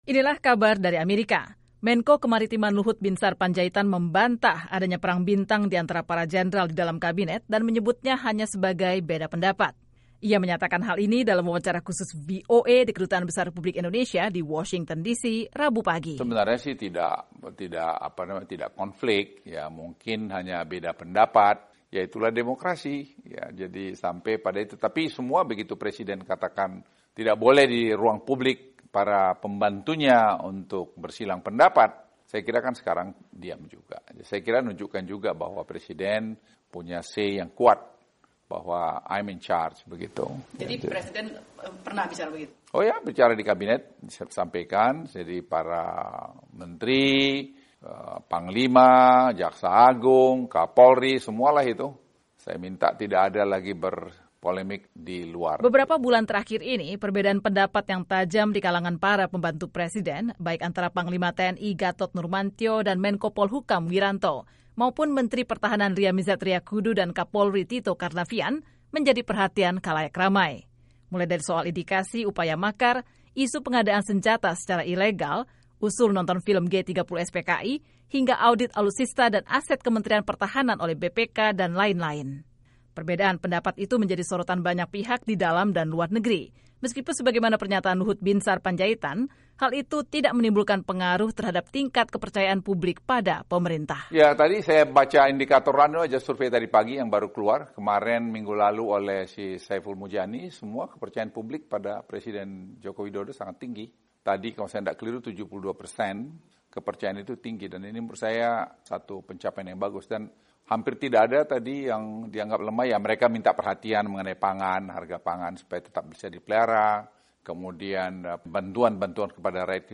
Luhut Binsar Panjaitan menyatakan hal ini dalam wawancara khusus VOA di Kedutaan Besar Republik Indonesia di Washington DC Rabu pagi (11/10).